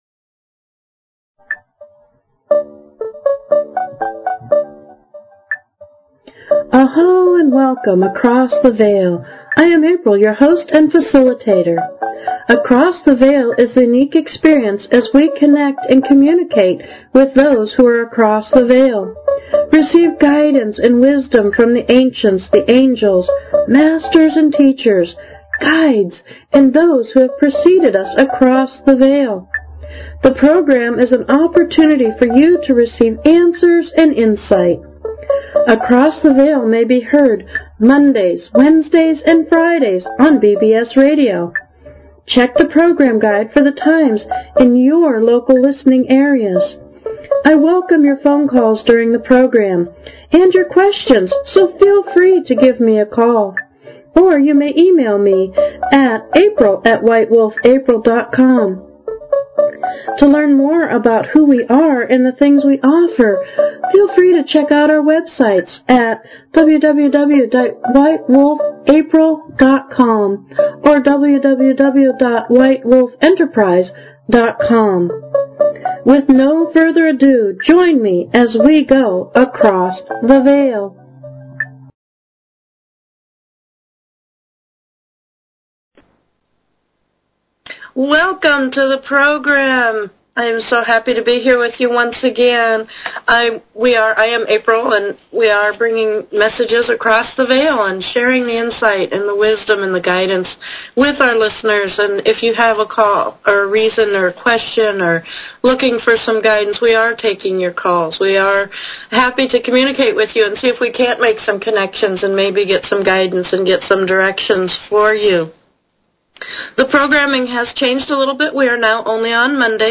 FREE Intuitive Readings Every Week, Every Show, For Every Call-In Across the Veil Please consider subscribing to this talk show.